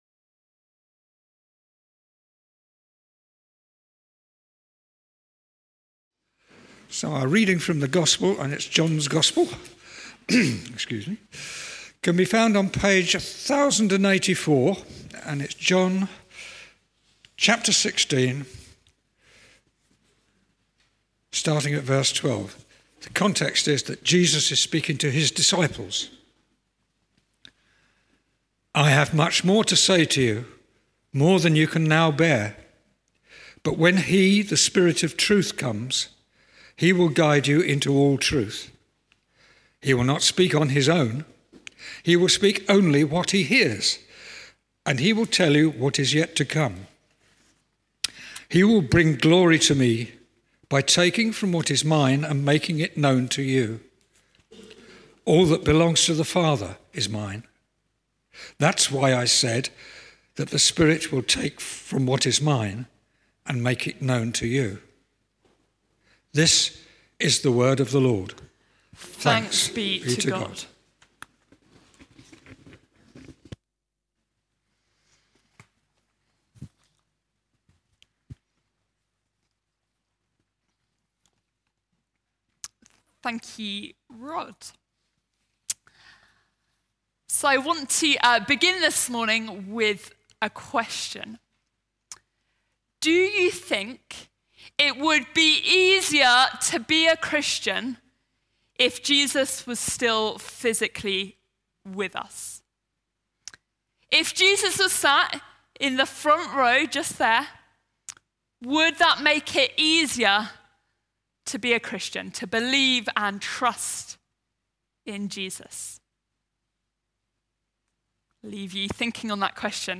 Series: Stand-alone sermons